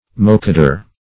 Mokadour \Mok"a*dour\, n.